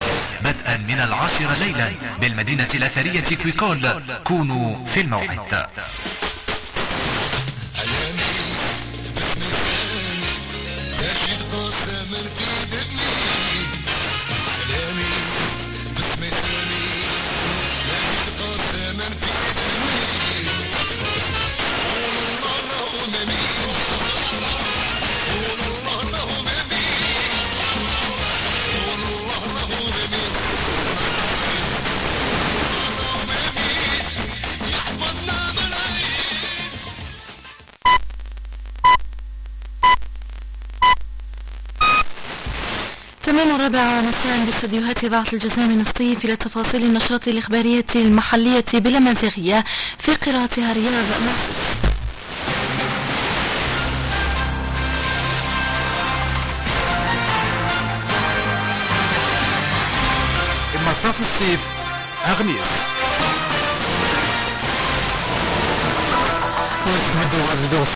90,4 MHz Algír rádió Setif - regionális adás ID-val